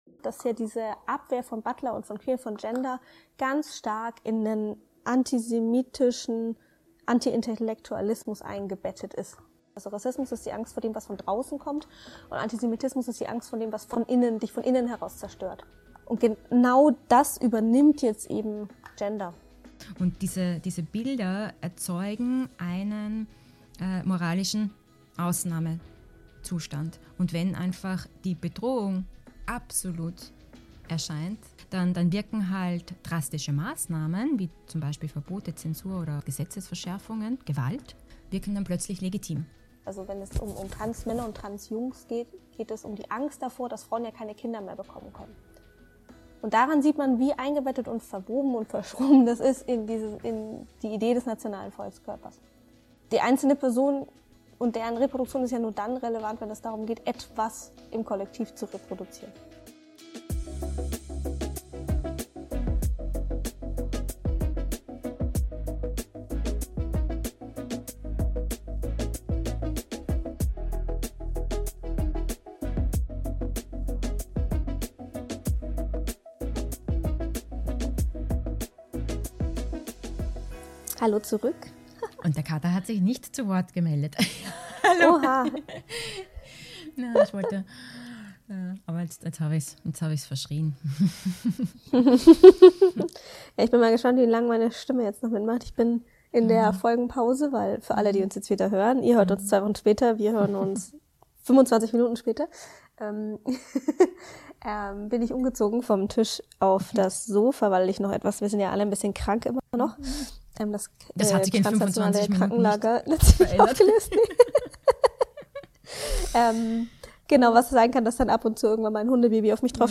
Wie funktioniert das Schreckgespenst „Gender“ im politischen Diskurs? Wir sprechen mit Judith Butler über Anti-Gender als Phantasma, über Angst, Allianzen, Religion, Nation, Faschisierungstendenzen — und darüber, wie kritische, feministische Praxis trotzdem handlungsfähig bleiben kann.